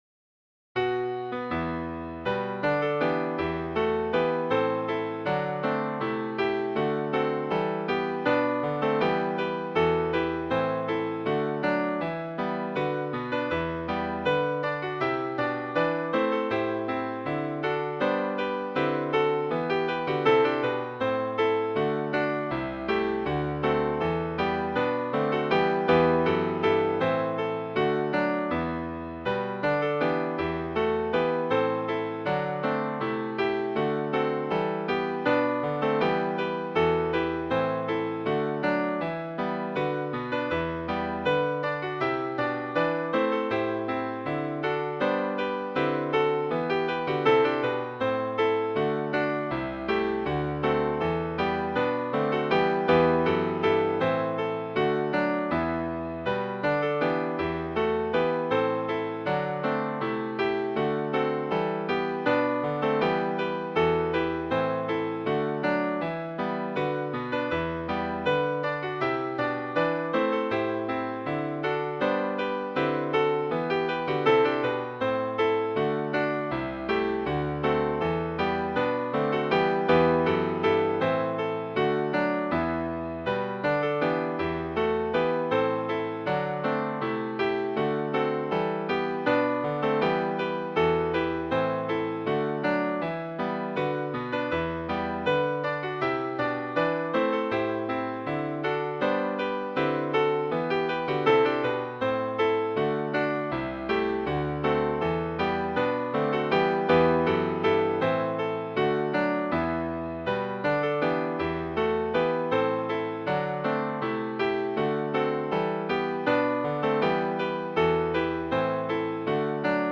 Midi File, Lyrics and Information to Jack Hall